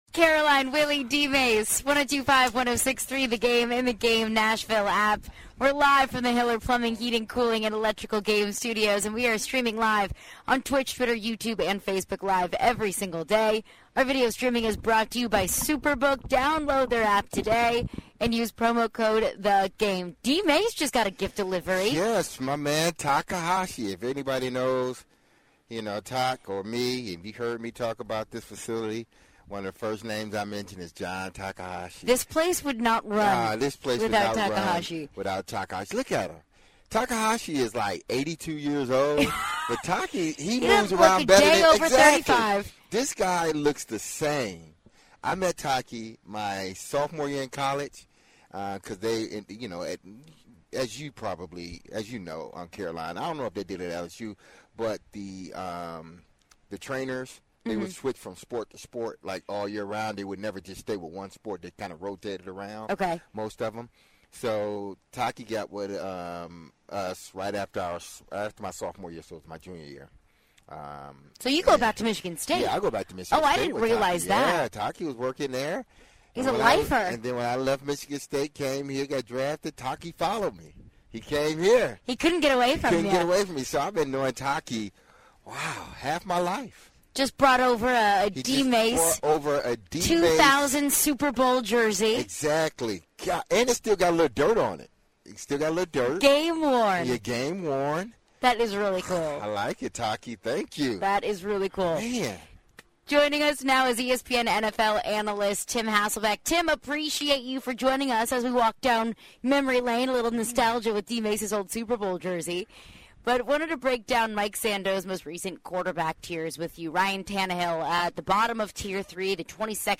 Tim Hasselbeck joins the show to discuss Titans training camp and much more.